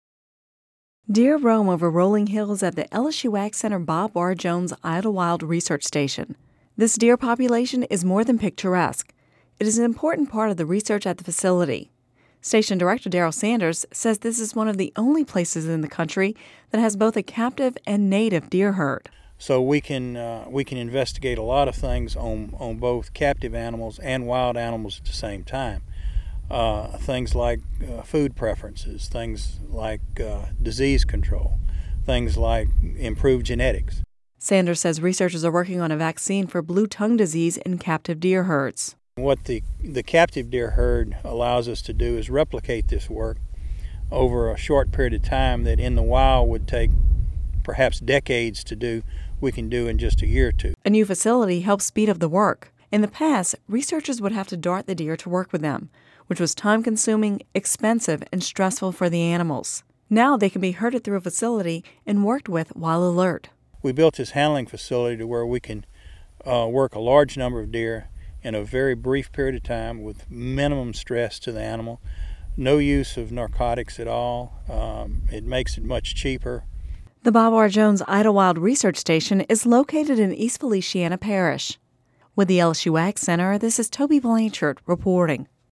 (Radio News 10/25/10) Deer roam over rolling hills at the LSU AgCenter's Bob R. Jones Idlewild Research Station. The population is more than picturesque; it is an important part of the research at the facility.